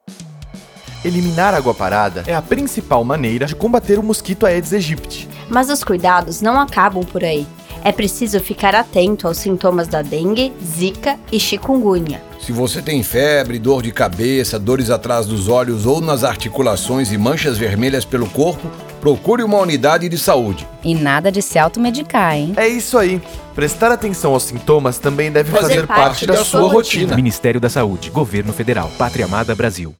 Spot - Mosquito Sintomas 30seg